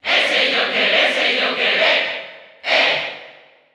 Category: Crowd cheers (SSBU) You cannot overwrite this file.
Joker_Cheer_Spanish_SSBU.ogg.mp3